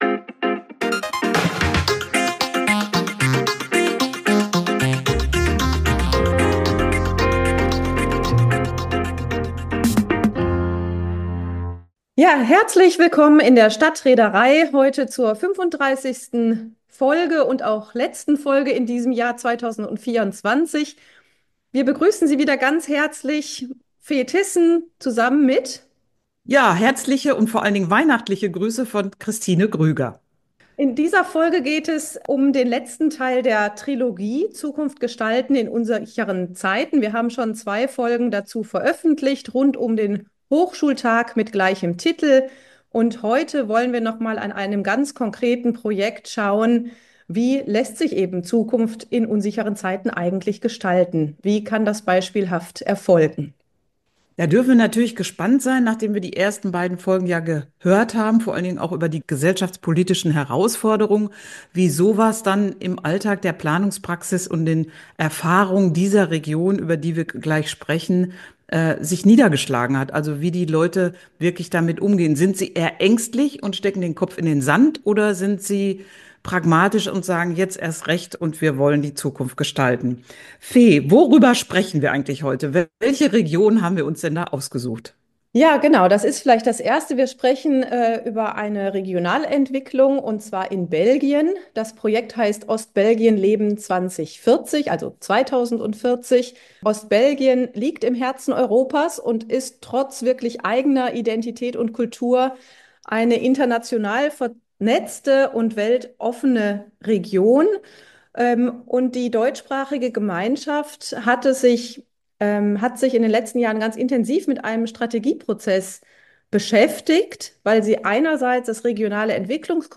Wir erörtern in dem Gespräch, wie der Spannungsbogen der übergeordneten globalen Trends hin zu lokalen Lebenswelten und ortsspezifischen Herausforderungen gelingen kann. Wir erfahren, wie beispielhaft dieser Prozess umgesetzt wurde und inwiefern Ostbelgien ein Vorbild sein kann für andere Zukunftsentwicklungen – hinsichtlich Digitalisierung, Mobilität im ländlichen Raum, Nachhaltigkeit etc. Wir wollen wissen, wie es in Ostbelgien gelungen ist, Menschen zur Teilhabe an solch großen Fragestellungen zu begeistern.